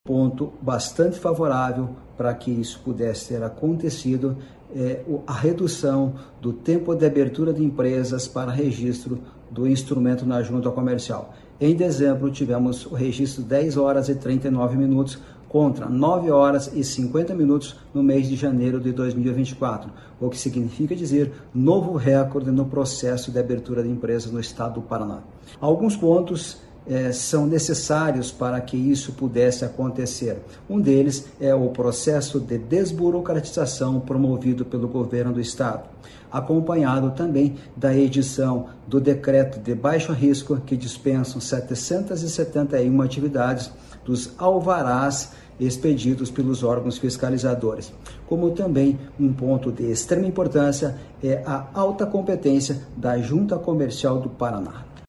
Sonora do presidente da Jucepar, Marcos Rigoni, sobre a abertura de 27 mil novas empresas em janeiro no Paraná